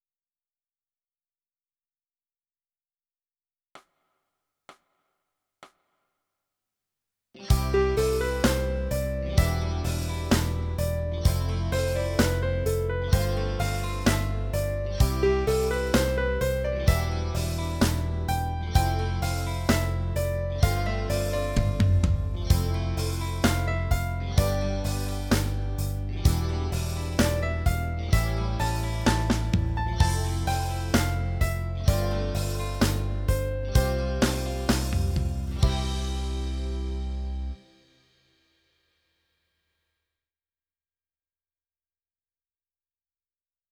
第2章〜第5章で使われる音源の文中聴音課題曲のダウンロードページです。
※曲の中には、無音部分が入っていることもあります。